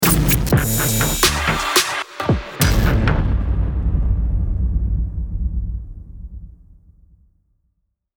FX – 118 – STAGER